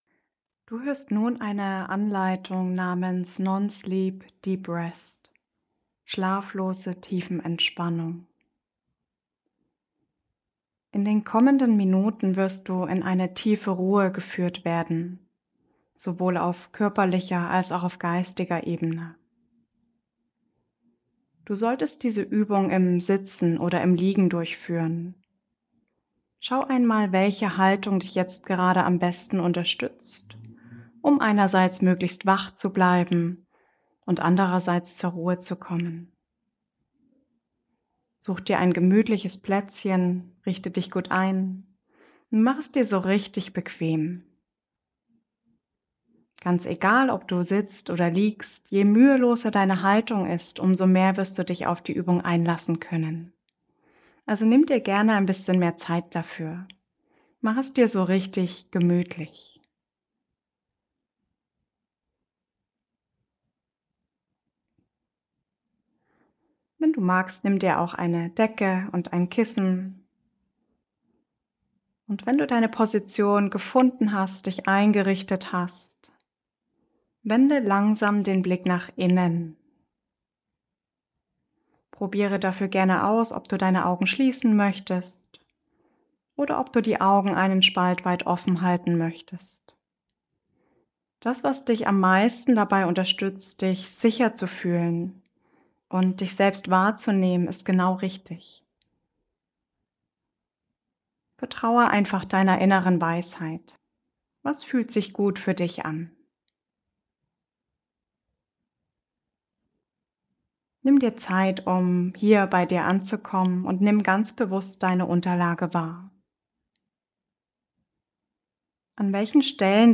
Meditation Non Sleep Deep Rest